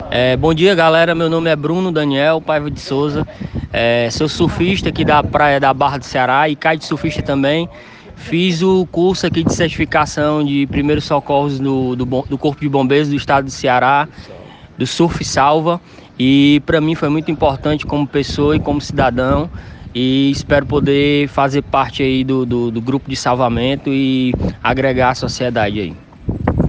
Depoimento